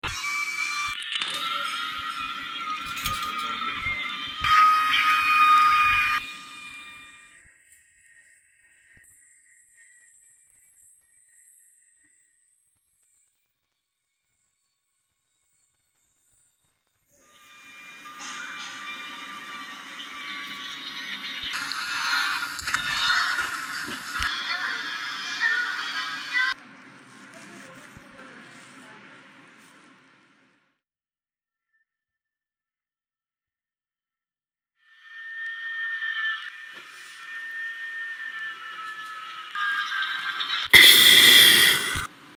Parte vocale